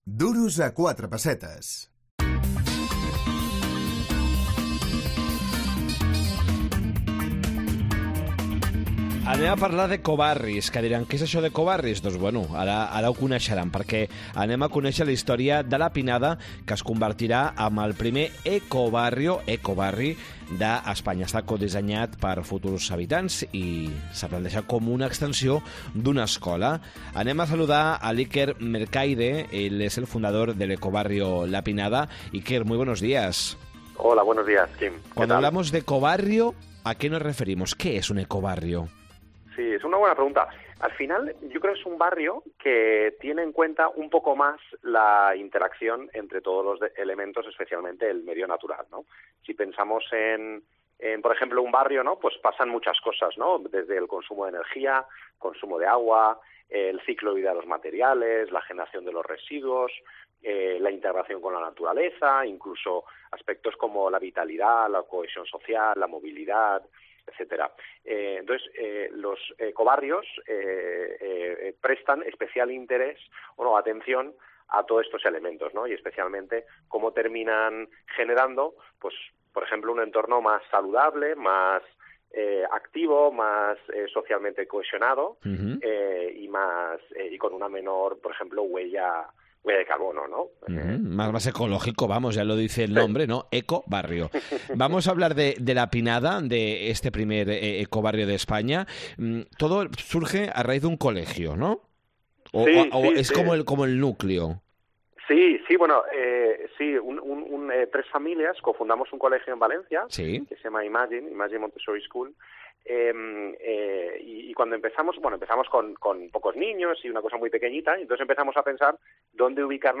Duros a cuatre Duros a quatre pessetes Què és un Ecobarrio? Entrevista